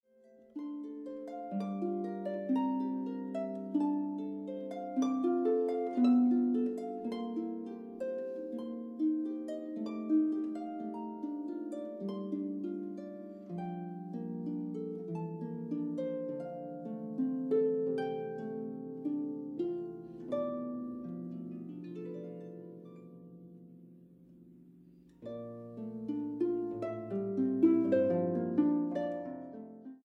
Boonkker Audio Tacubaya, Ciudad de México.